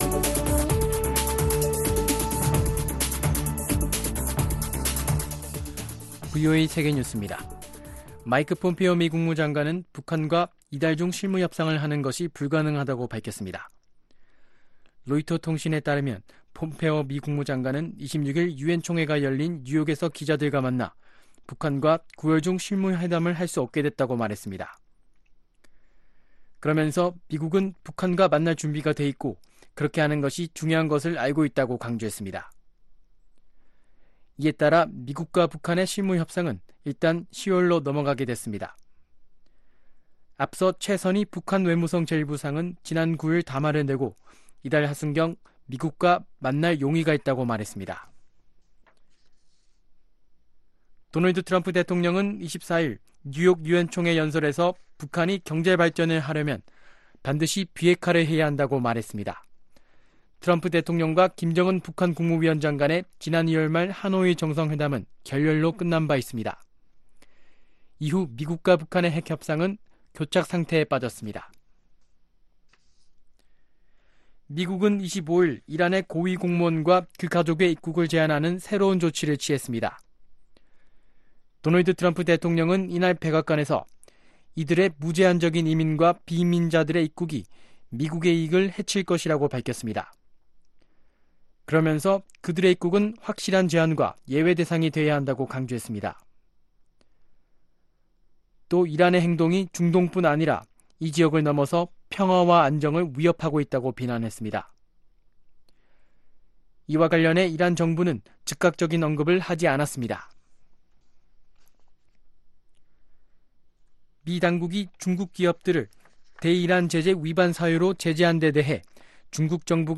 VOA 한국어 아침 뉴스 프로그램 '워싱턴 뉴스 광장' 2019년 9월 27일 방송입니다. 북한의 ‘단계적 비핵화’가 성공하려면 북한 핵시설의 투명한 신고가 선결돼야 한다고 미국 상원 민주당의원들이 밝혔습니다. 한-일 양국의 갈등은 동북아 평화와 번영을 수호하려는 미-한-일 3자 협력을 저해한다고 미 국무부 한국-일본 담당 동아태 부차관보가 말했습니다.